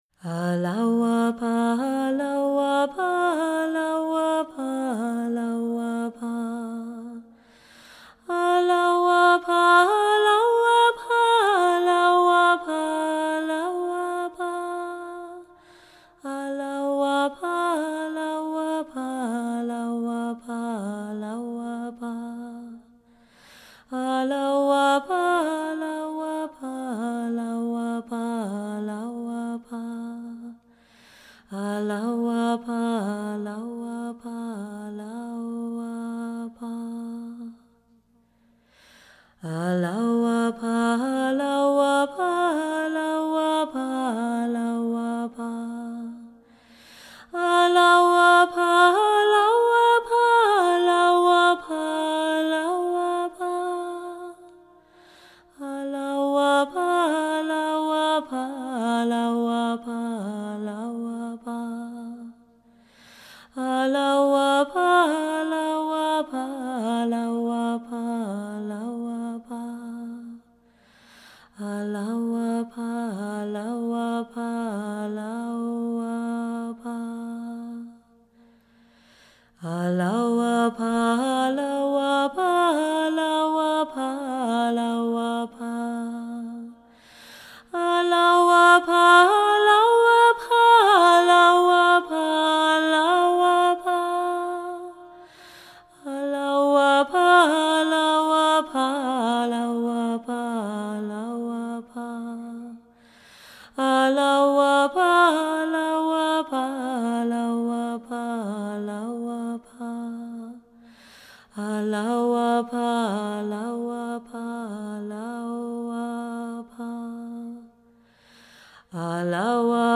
solo zikr
traditional Baha'i prayer